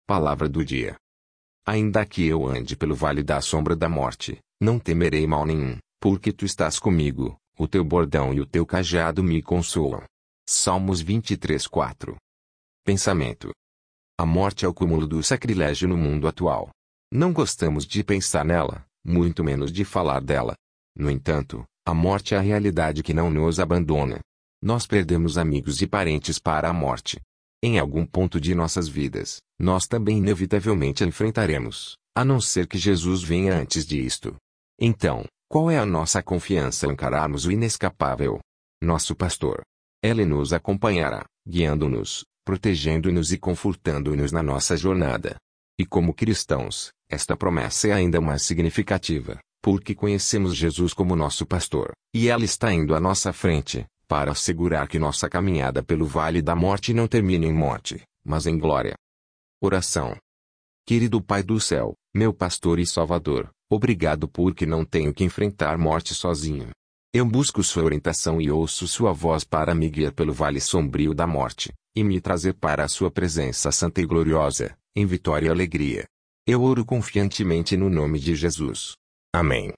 audio27desetembro-masculino.mp3